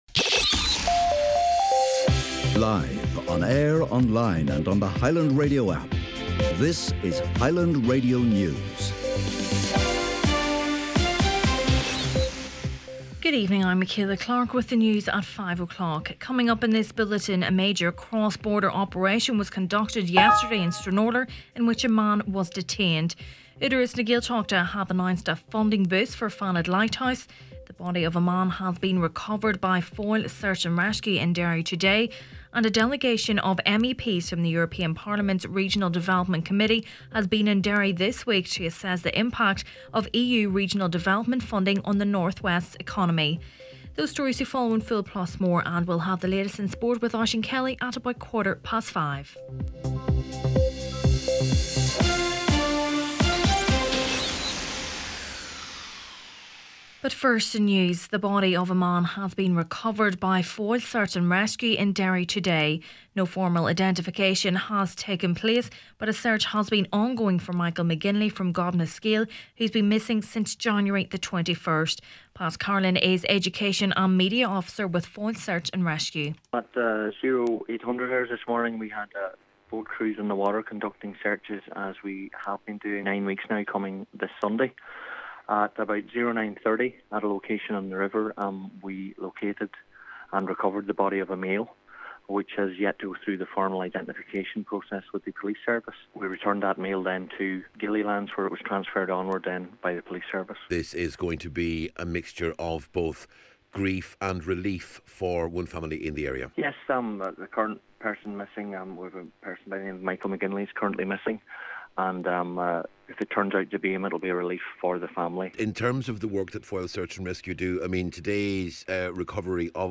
Main Evening News, Sport and Obituaries Friday 23rd March